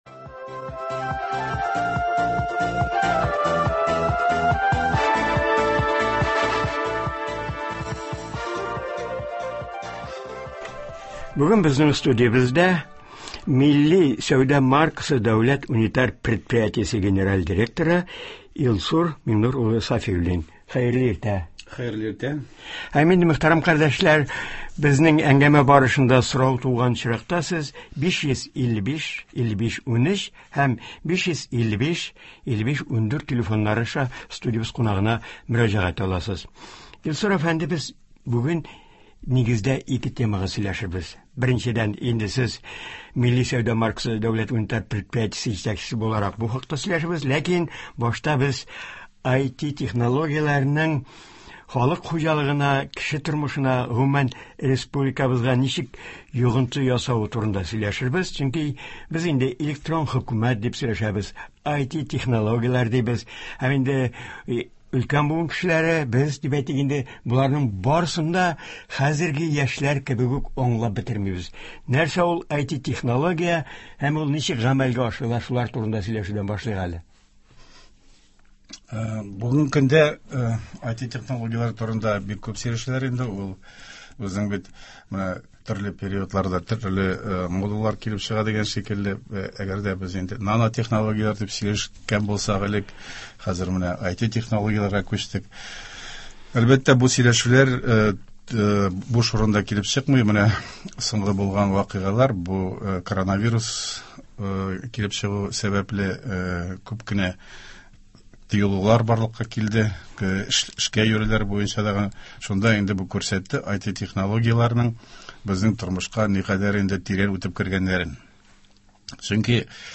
телефон аша тыңлаучылар белән фикерләшәчәк.